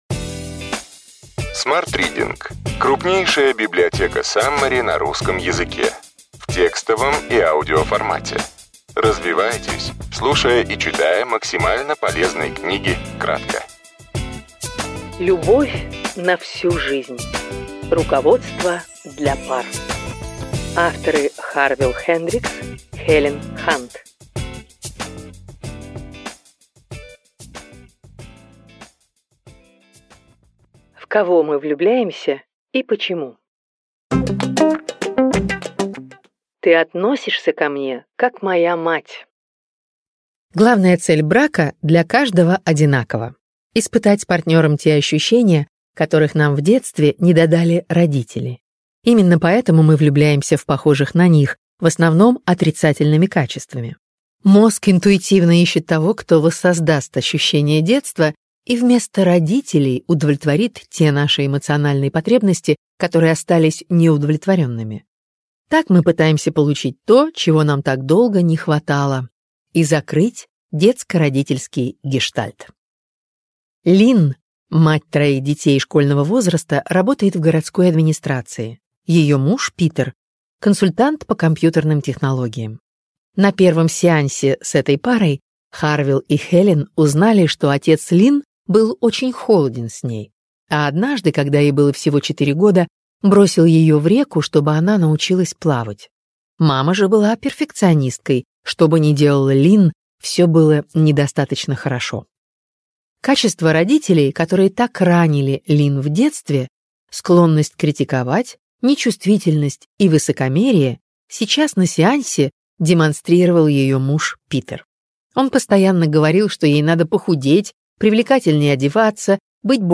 Аудиокнига Любовь на всю жизнь. Руководство для пар.